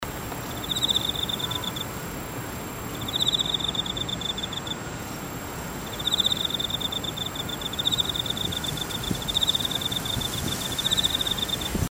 近づくと、音、止めちゃうんですよねえ。
天空の至る所で、秋の音色が聞こえます。
エンマコオロギの音色、mp3でお楽しみください。
高層のため、強風の音も入っていますケドね。